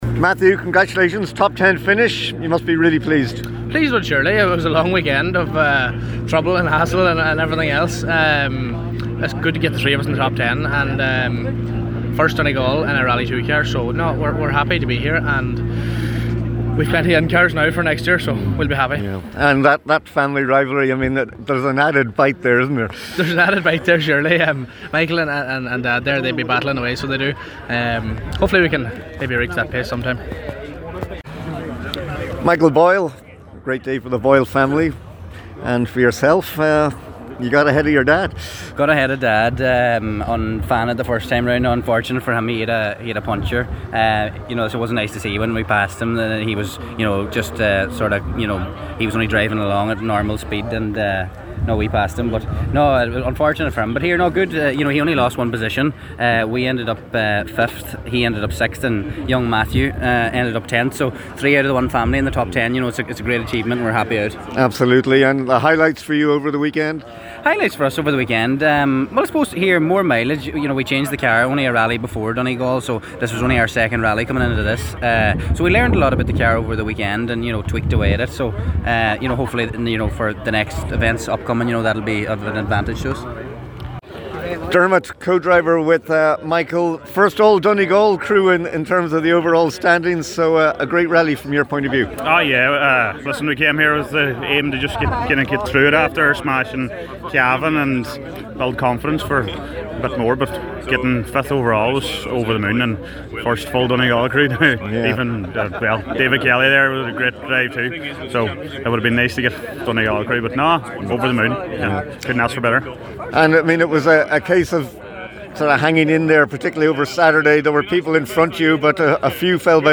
Top competitors react to enthralling Donegal International Rally – Finish-line chats